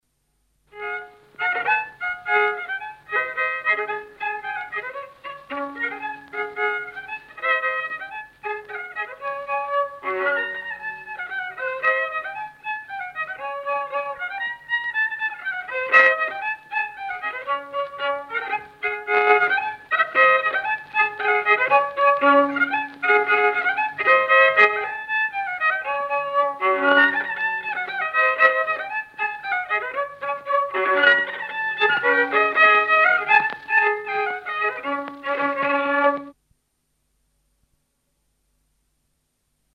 Polka "Ei jäta ma"